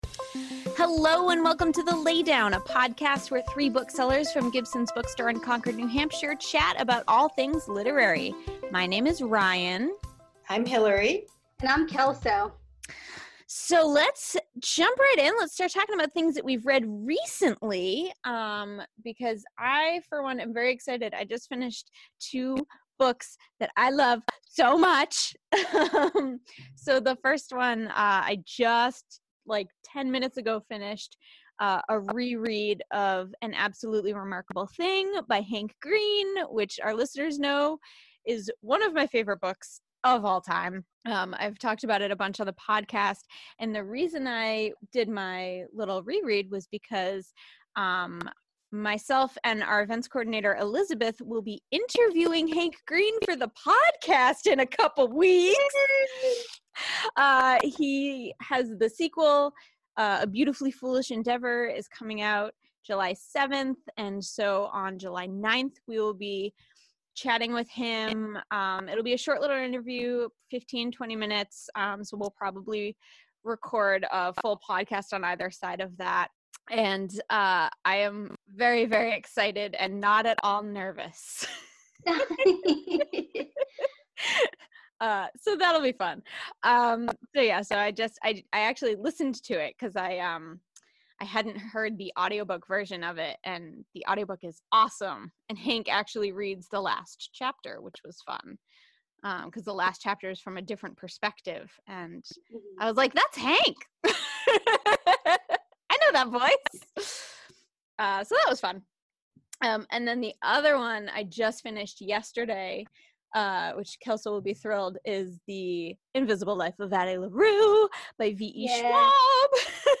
Read with Pride! (Recorded Remotely)